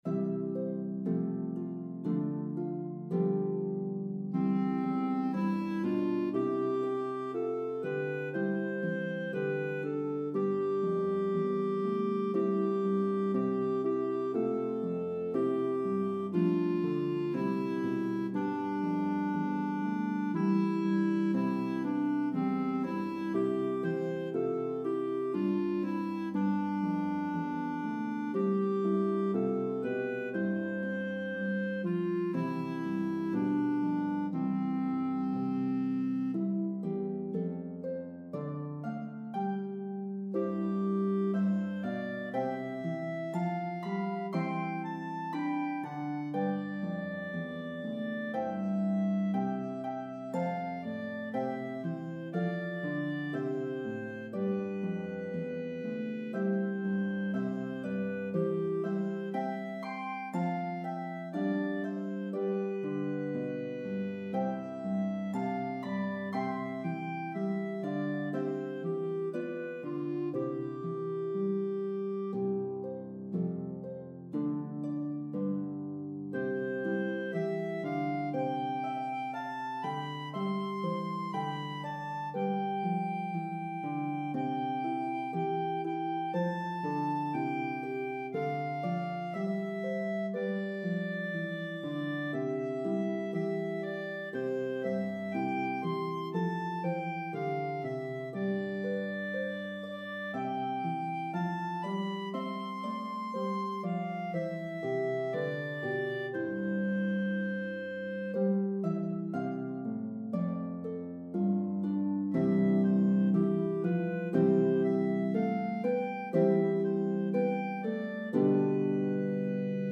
Playable on Lever or Pedal Harps.